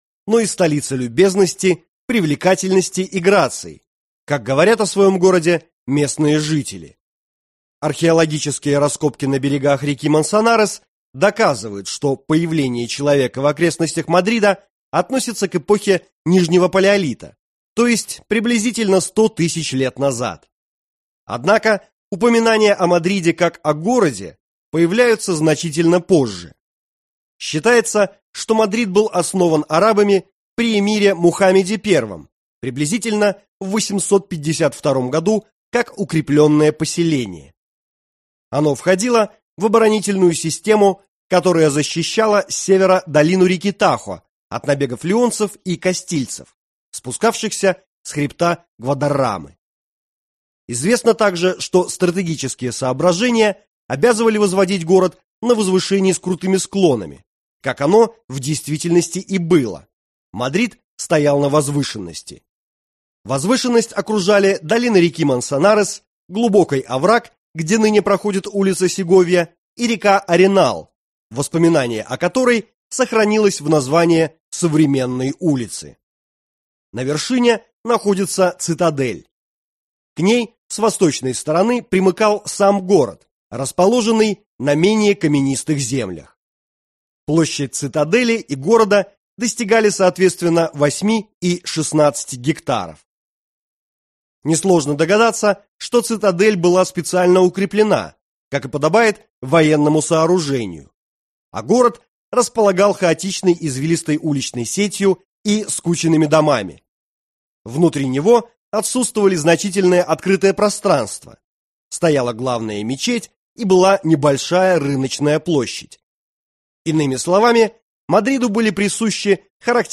Аудиокнига Путеводитель по Мадриду | Библиотека аудиокниг